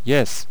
archer_select5.wav